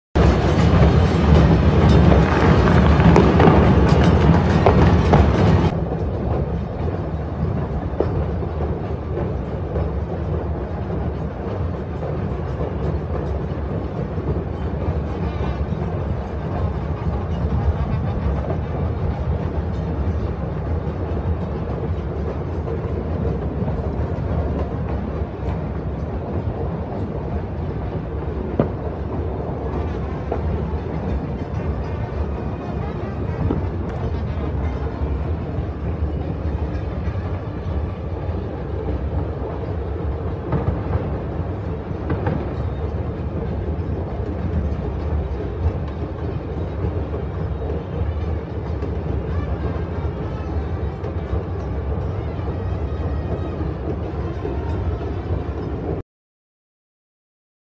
Grossdemonstration gegen Explosion der Lebenshaltungskosten